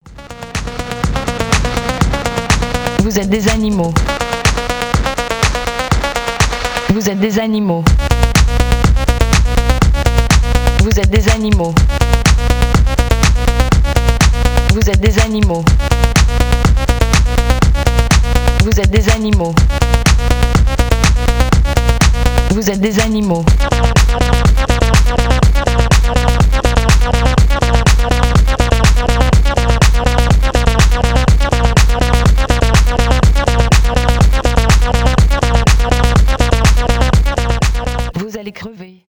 electronic , техно , electro house , experimental